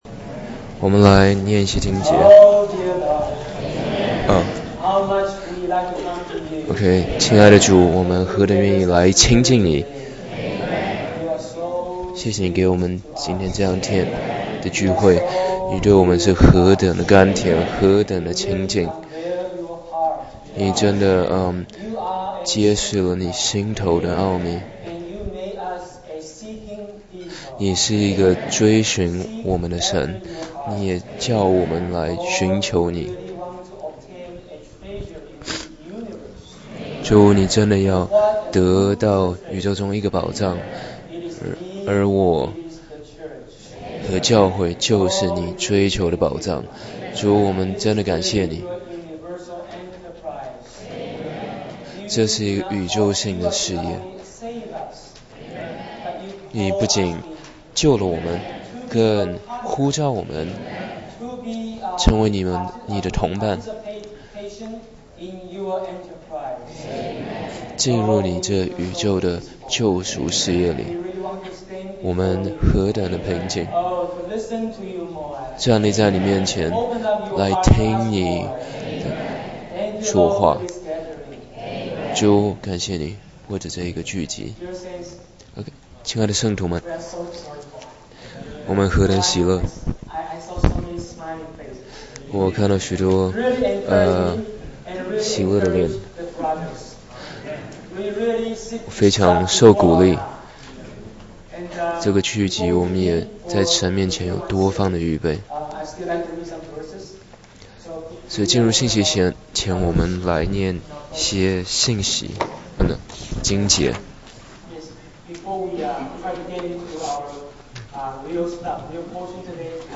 2010.2.7 主日–Jubilee